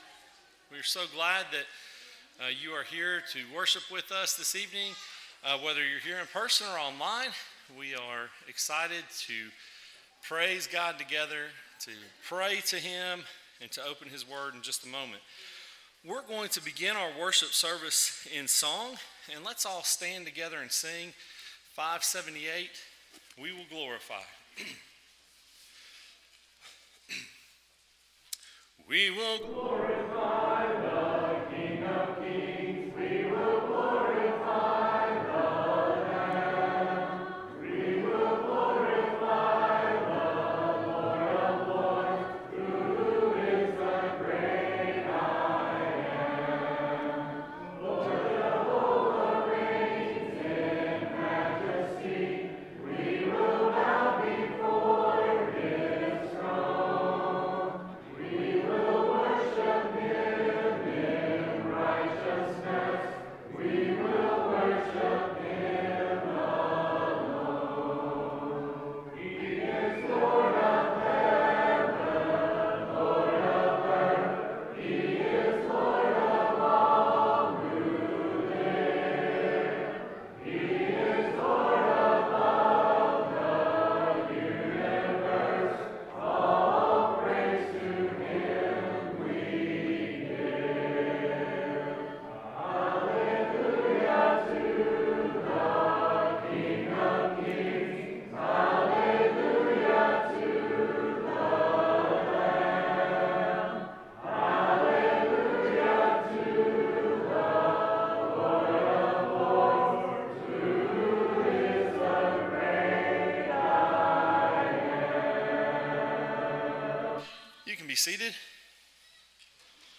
Matthew 5:20, English Standard Version Series: Sunday PM Service